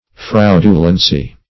Search Result for " fraudulency" : The Collaborative International Dictionary of English v.0.48: Fraudulence \Fraud"u*lence\ (?; 135), Fraudulency \Fraud"u*len*cy\, n. [L. fraudulentia.]
fraudulency.mp3